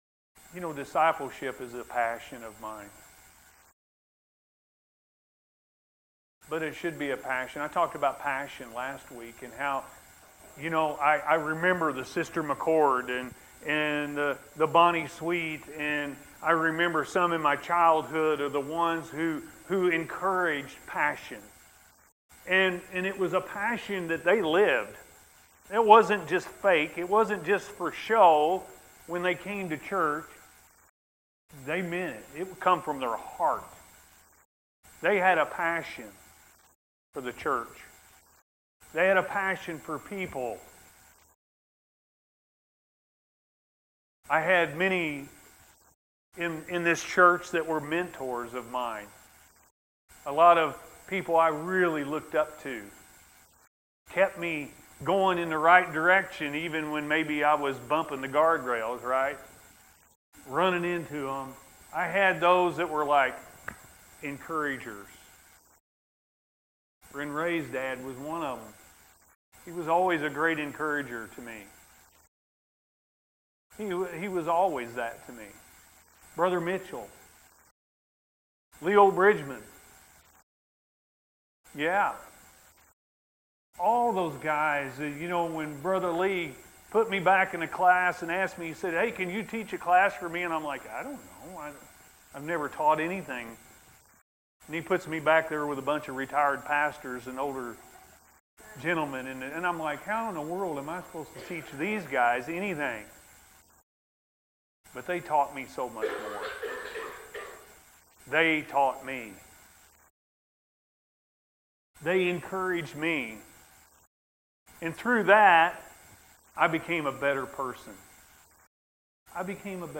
Discipleship Is A Passion-A.M. Service – Anna First Church of the Nazarene